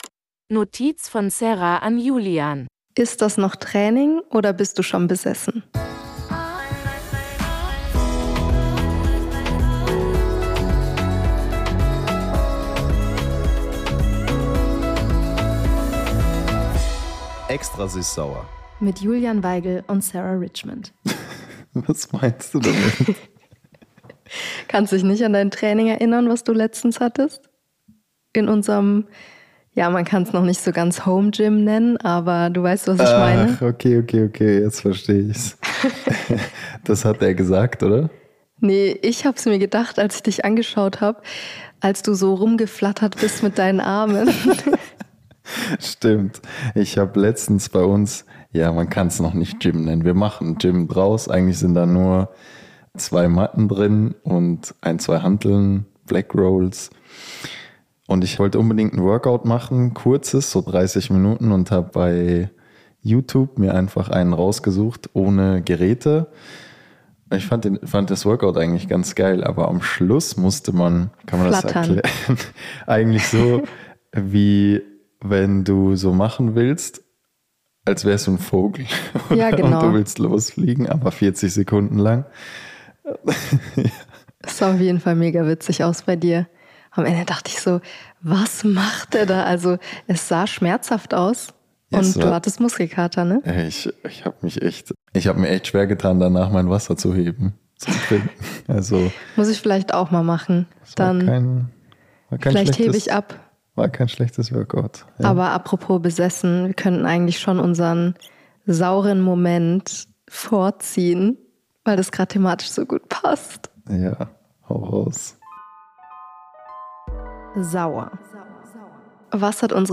Paranormale Aktivitäten und ein heißes Eröffnungsspiel – Late-Night-Talk ~ Extra süß sauer Podcast